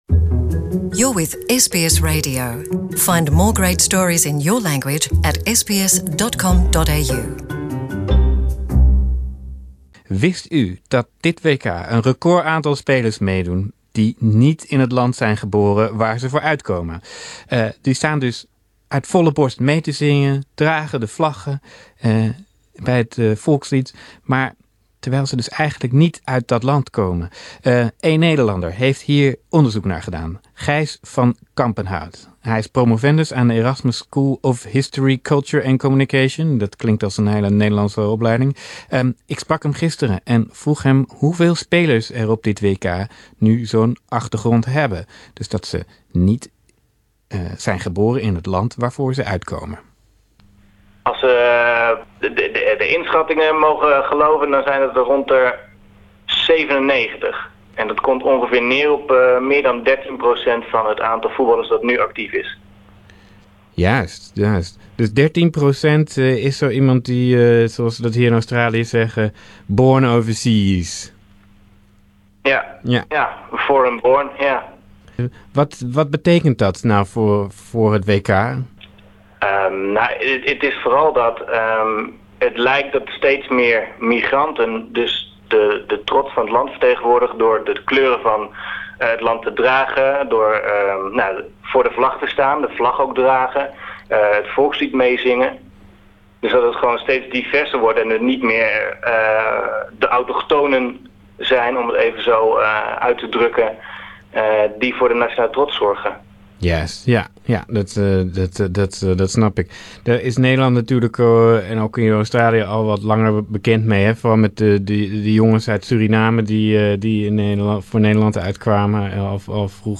We spraken hem en vroegen hem ook hoe Australië het doet op de 'multiculti ranglijst' van dit WK.